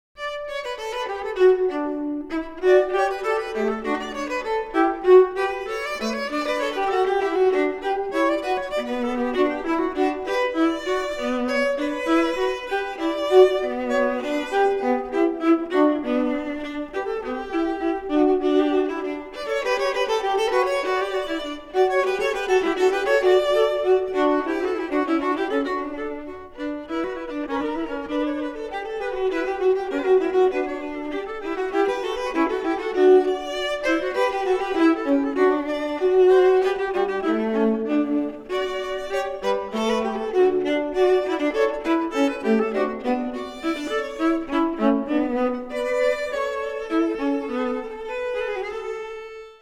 LISTEN 1 Allegro
Recorded at: Music Works Recordillg Studios,